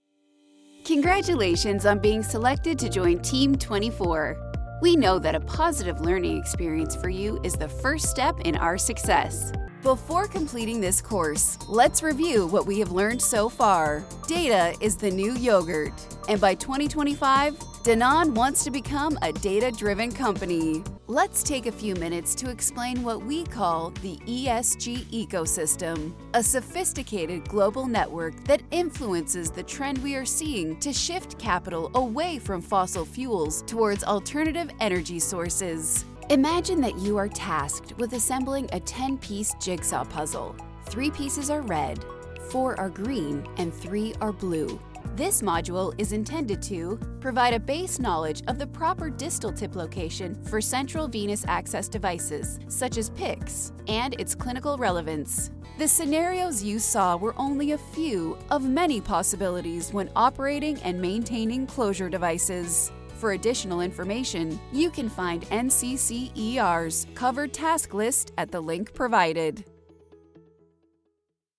Commercial Demo
English - USA and Canada
Young Adult
Middle Aged